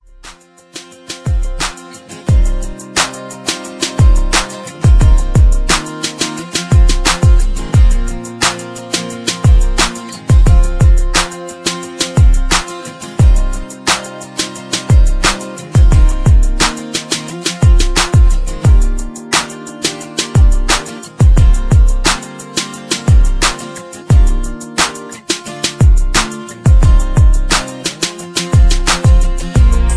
(Key-Bb) Karaoke Mp3 Backing Tracks